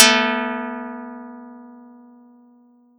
Audacity_pluck_2_14.wav